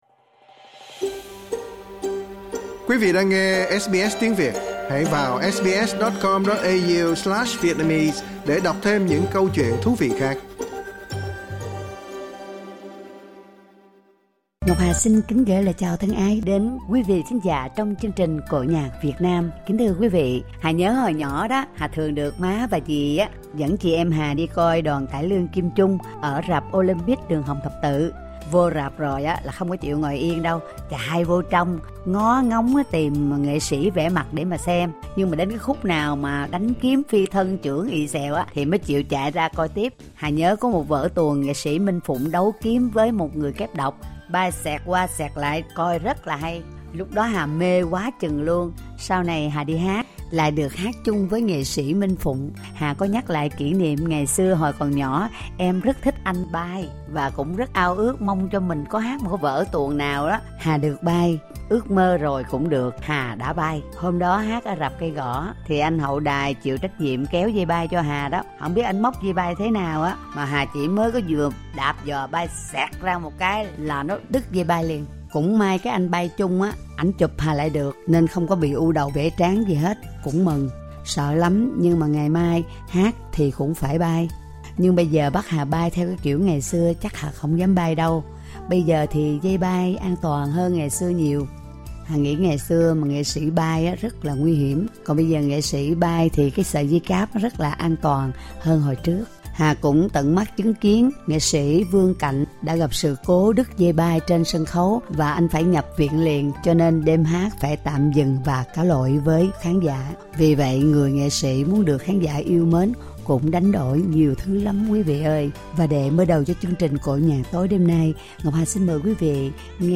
cải lương
vọng cổ